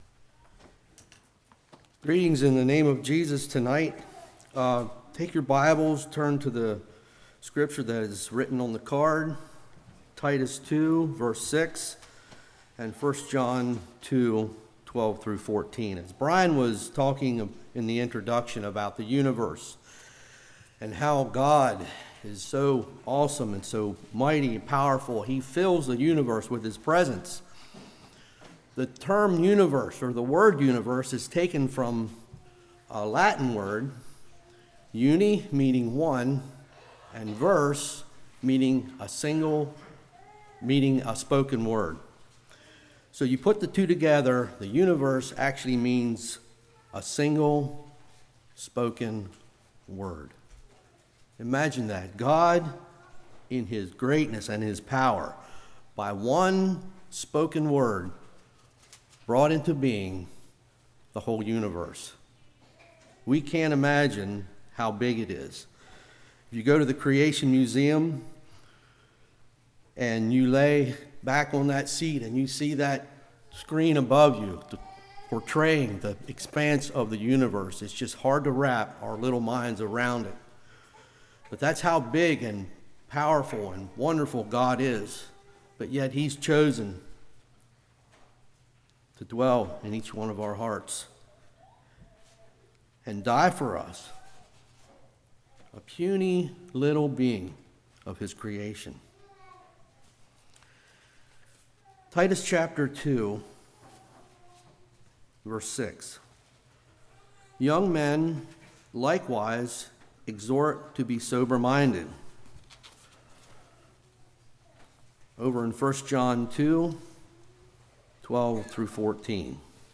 Spring Revival 2019 Passage: Titus 2:6, 1 John 2:12-14 Service Type: Revival They Are Spiritually Weak What Does God Want From Me?